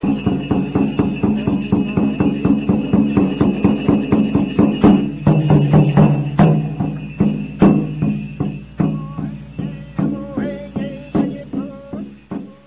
KULTRUN
Timbal
Membranófono, de golpe, directo.
Se ejecuta de dos maneras: sostenido en la mano y percutido con una baqueta o apoyado en el suelo y percutido con dos baquetas.
Ensamble: kultrún, cascawillas, voz
Procedencia, año: Maquehue, Provincia de Cautín, IX Región, Chile, 1978